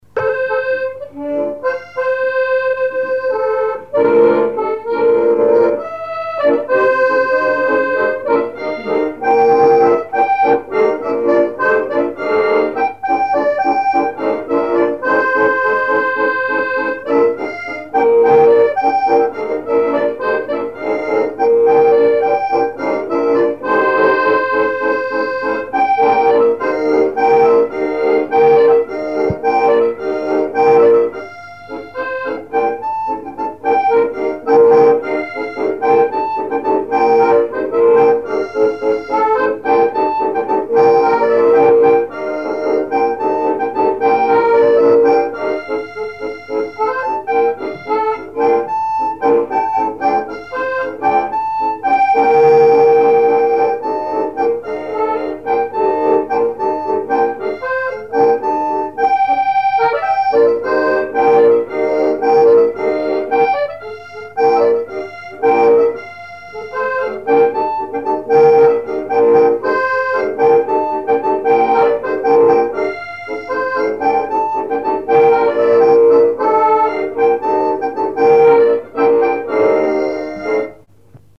danse : ronde : grand'danse
instrumentaux à l'accordéon diatonique
Pièce musicale inédite